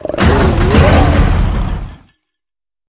Cri de Koraidon dans sa forme Finale dans Pokémon Écarlate et Violet.
Cri_1007_Finale_EV.ogg